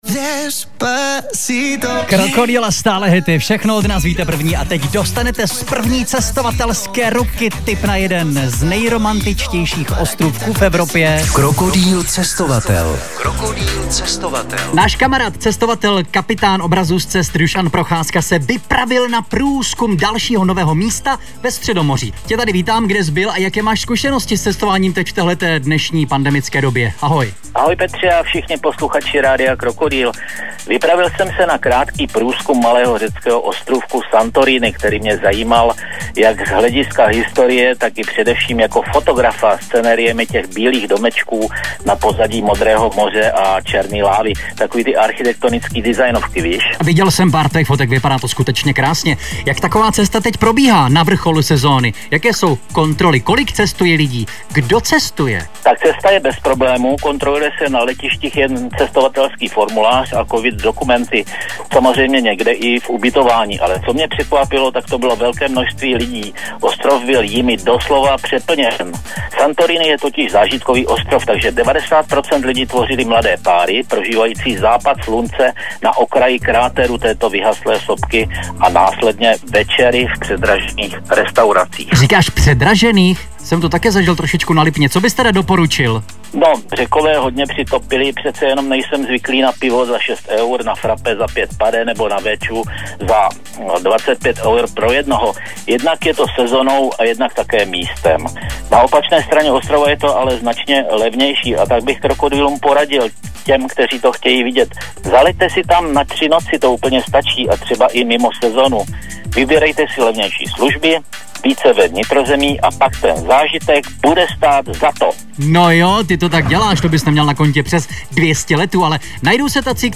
Co zdokumentoval i jeho reportáž pro rádio Krokodýl najdete
Santorini-reportaz.mp3